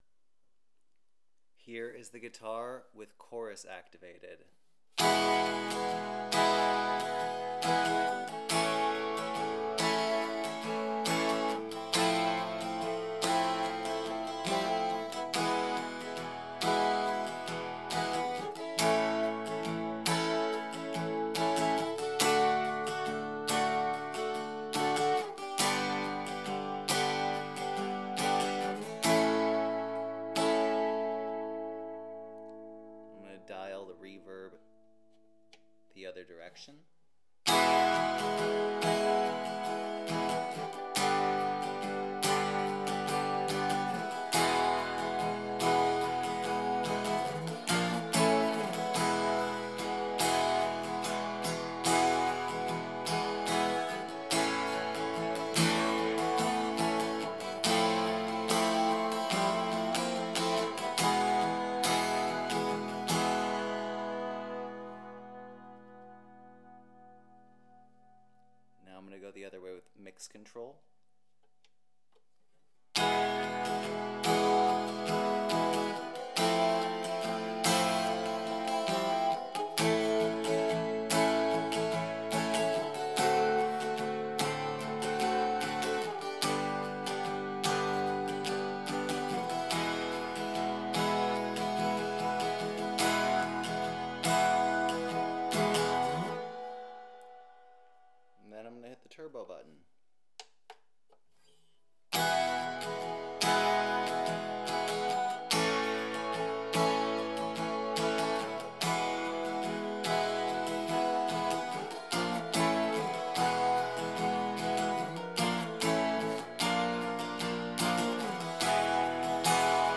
Here are some audio samples of how the guitar sounds acoustically. Both with and without effects. This was recorded using my Olympus LS-100 stereo recorder.
(Chorus playing chords)